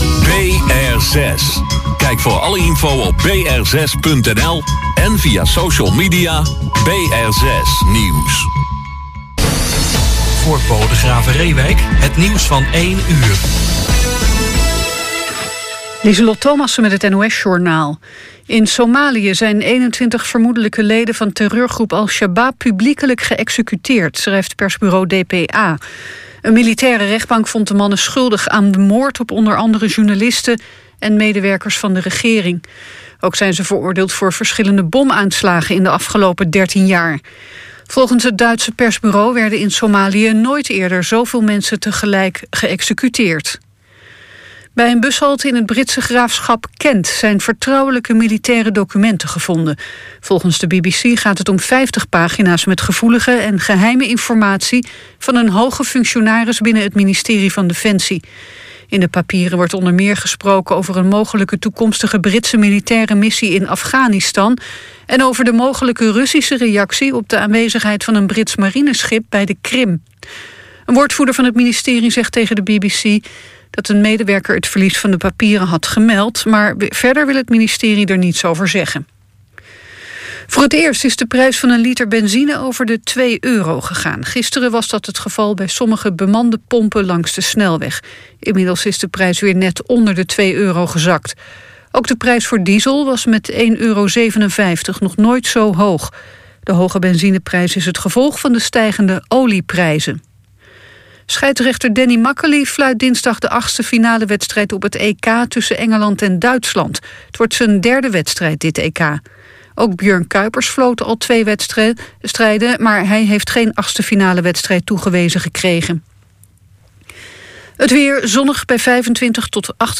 Zang – Gitaar
Contra bas
Steel gitaar